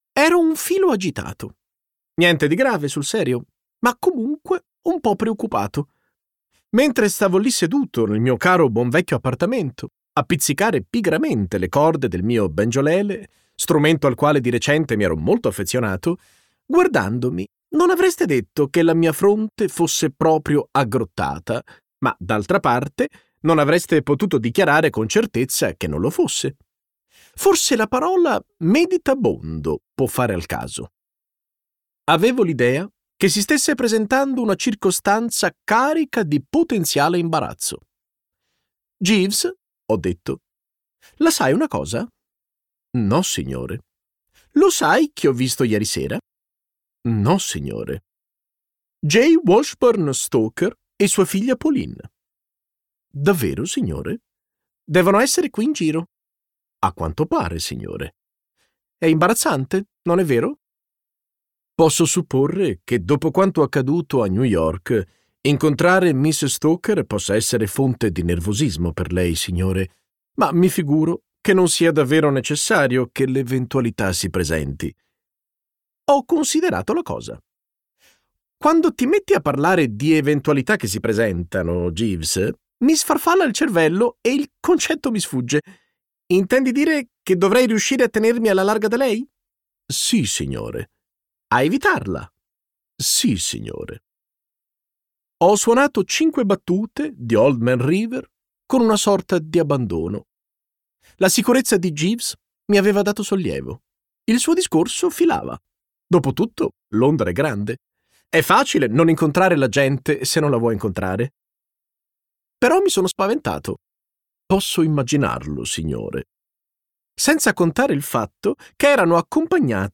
Versione audiolibro integrale